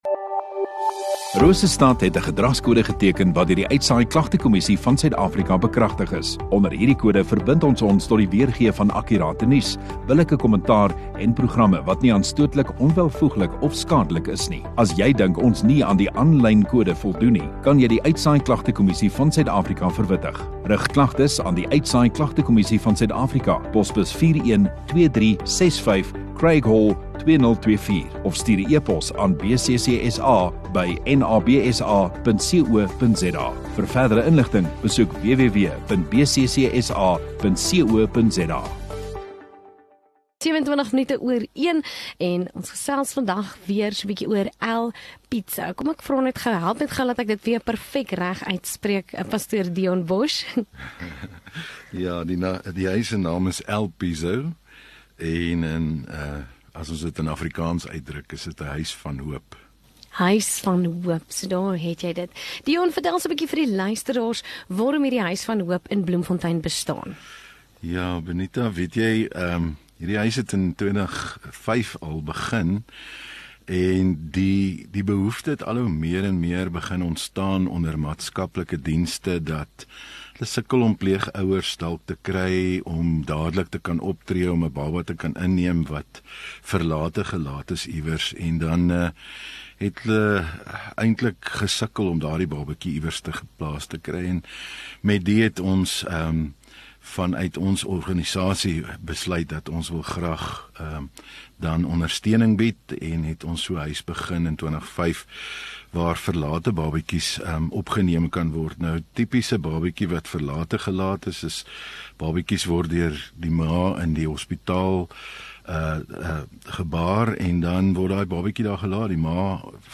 Radio Rosestad View Promo Continue Radio Rosestad Install Gemeenskap Onderhoude 6 Jun Huis van Hoop